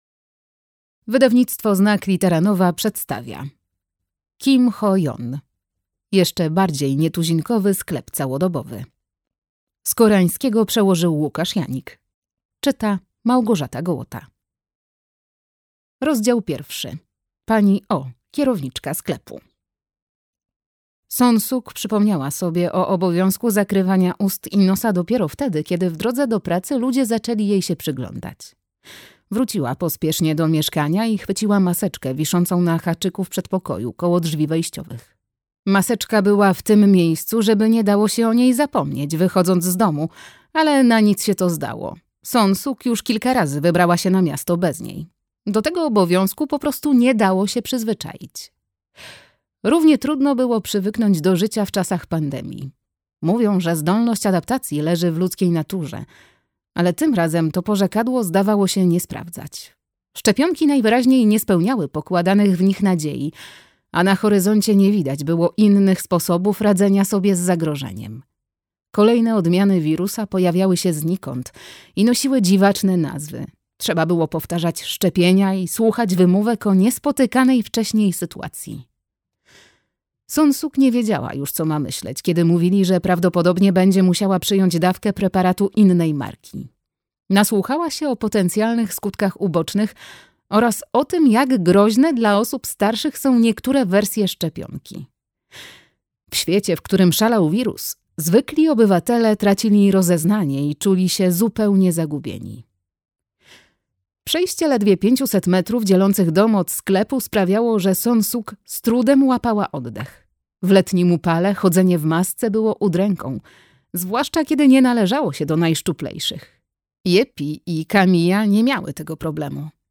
Jeszcze bardziej nietuzinkowy sklep całodobowy - Kim Ho-Yeon - audiobook - Legimi online